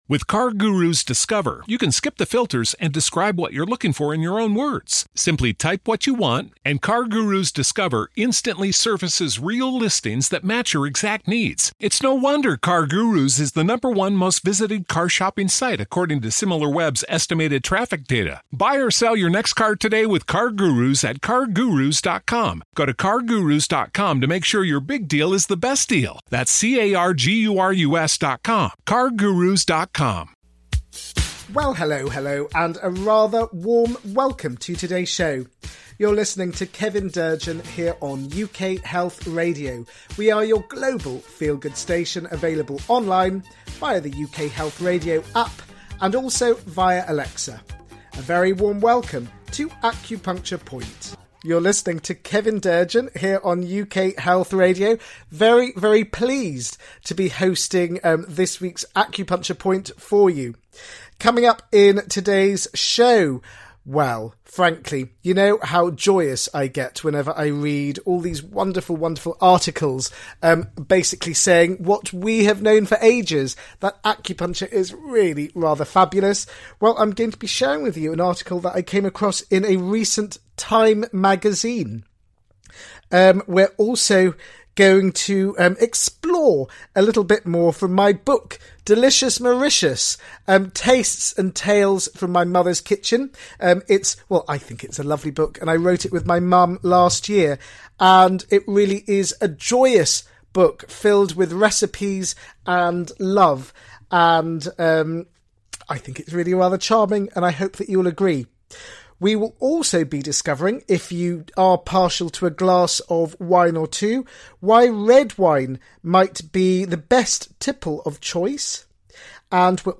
He will also play some gorgeous music to uplift your soul and get your feet tapping with happiness.